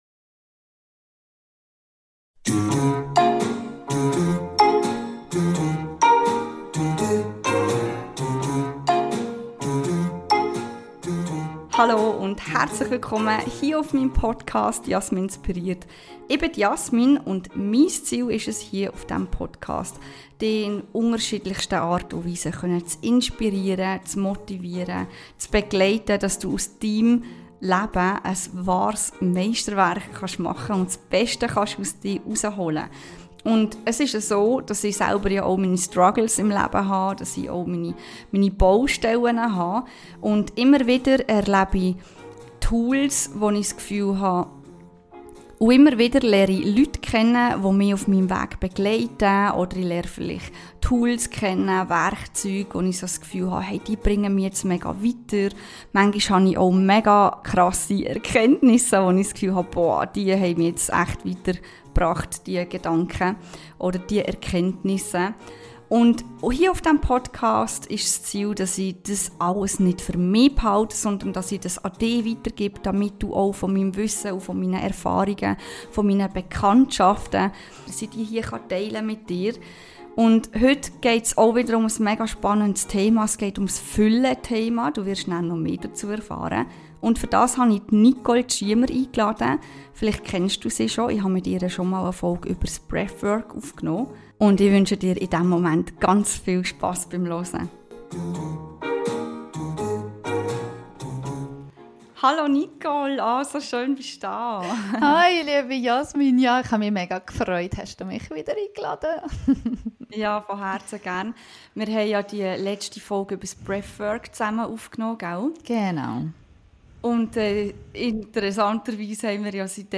Ein intensives Programm in welchem du die Möglichkeit hast das Thema Fülle tiefgehend für dich anzugehen. Sprache: Schweizerdeutsch